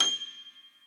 b_piano1_v100l32-3o8g.ogg